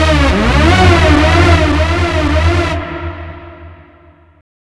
OLDRAVE 3 -L.wav